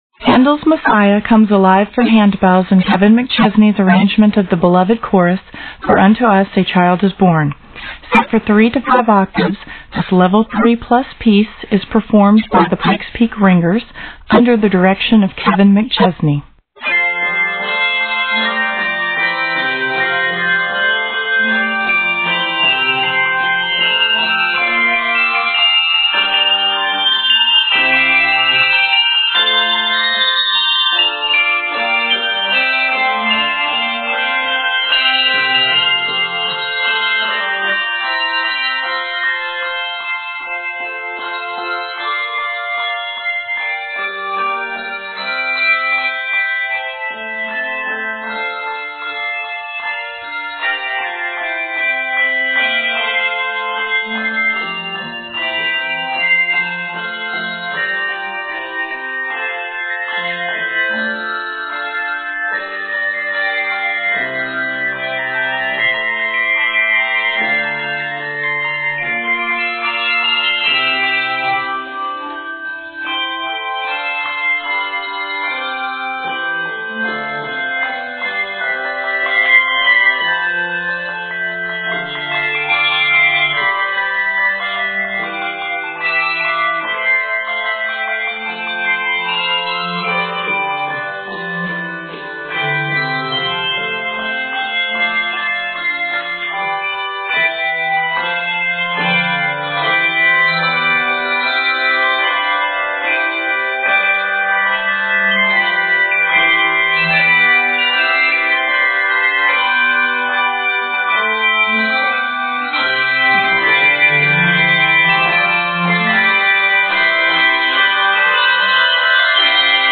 comes alive for handbells